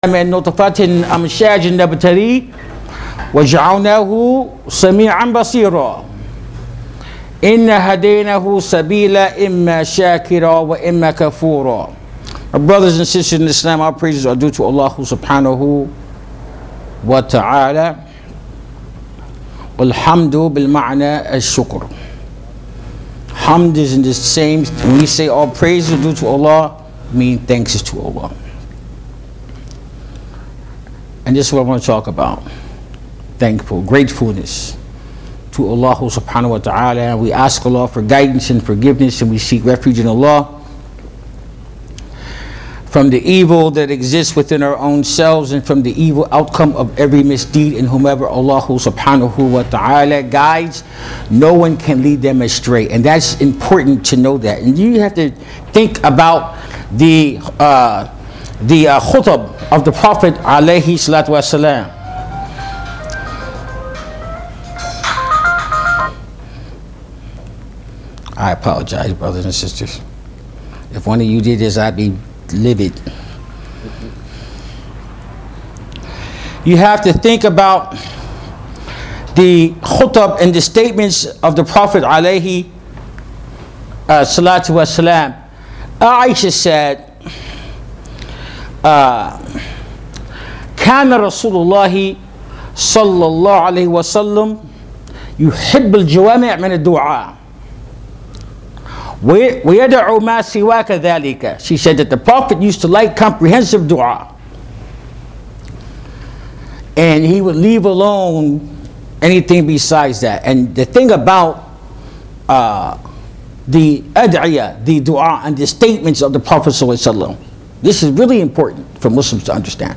Free Audio Khutba